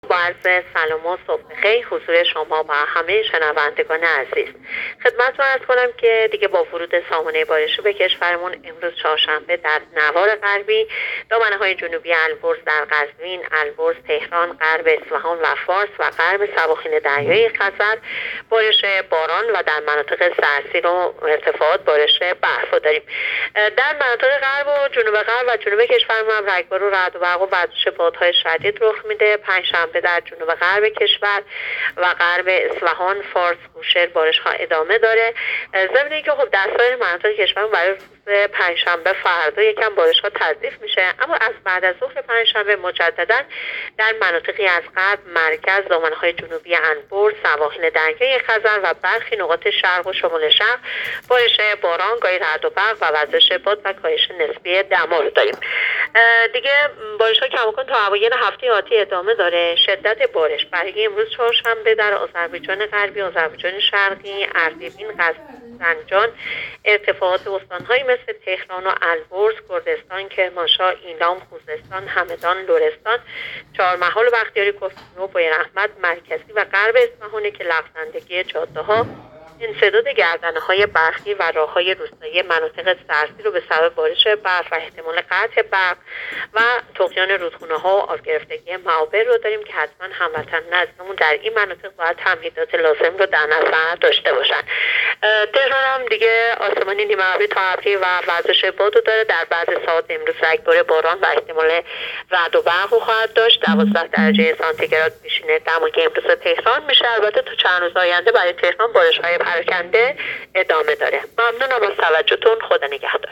گزارش رادیو اینترنتی پایگاه‌ خبری از آخرین وضعیت آب‌وهوای ۱۹ آذر؛